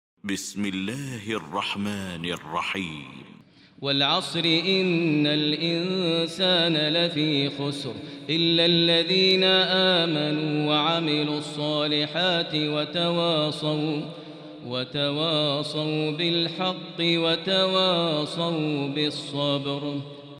المكان: المسجد الحرام الشيخ: فضيلة الشيخ ماهر المعيقلي فضيلة الشيخ ماهر المعيقلي العصر